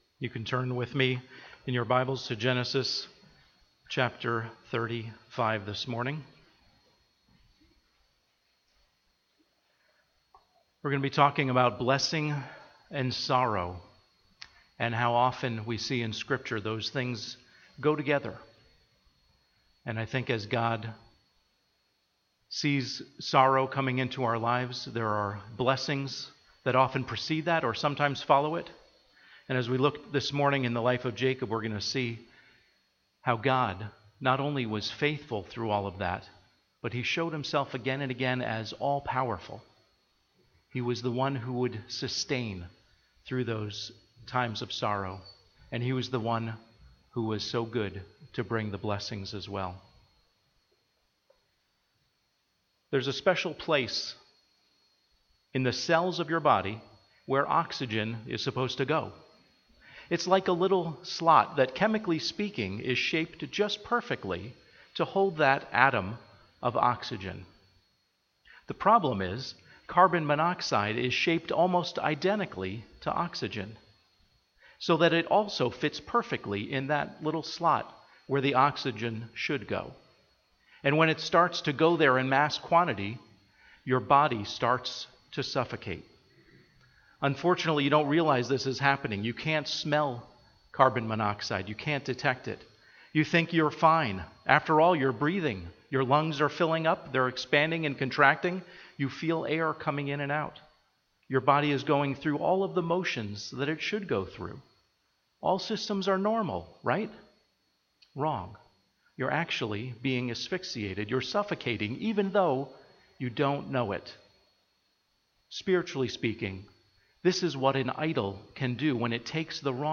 Home › Sermons › Blessing & Sorrow